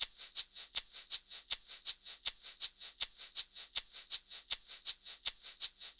Shaker 04.wav